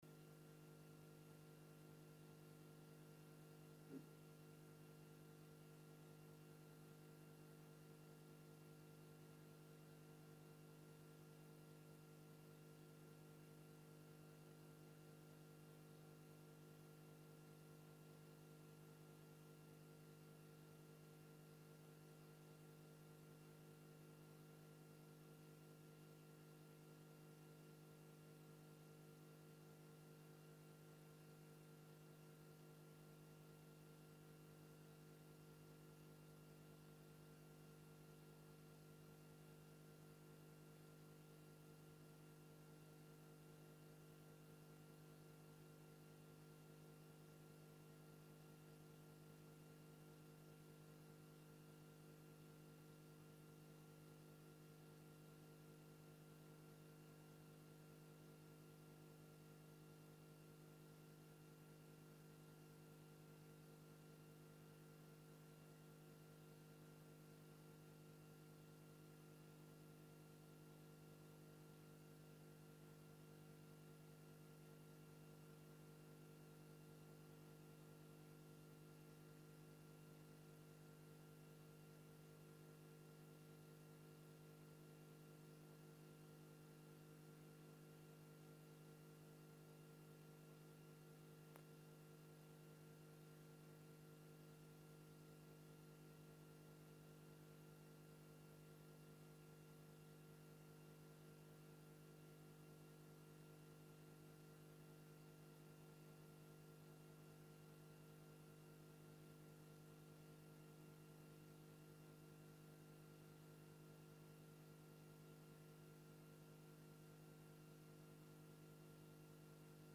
Locatie Druten, raadzaal Voorzitter C.A.A. van Rhee - Oud Ammerveld Toelichting De voorlopige agenda is vastgesteld door het Presidium en aangevuld met het advies van het RTG. Agenda documenten Agendabundel 21 MB Geluidsopname - raadsvergadering - 26 januari 2023 (geluid start op 5:48 min) 90 MB Besluitenlijst_Raad_openbaar_Druten_26_januari_2023 47 KB